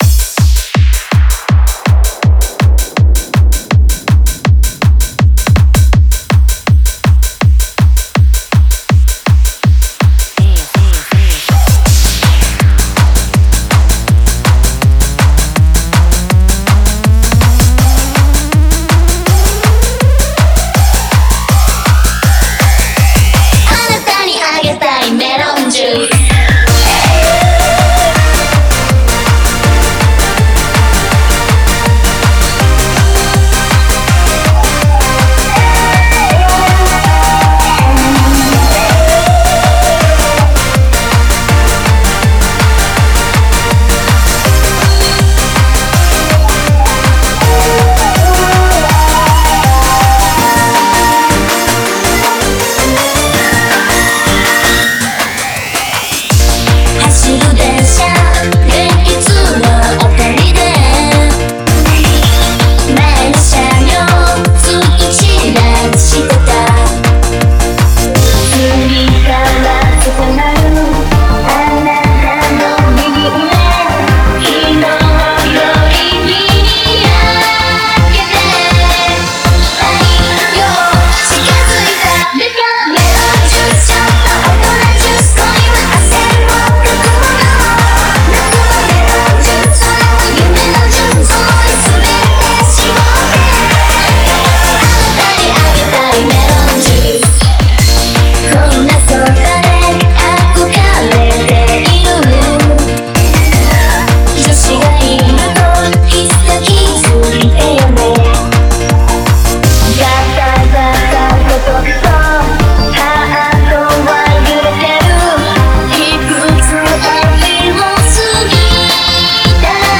Genre : Eurobeat / J-Pop / Idol
BPM : 162 BPM
Release Type : Bootleg / Digital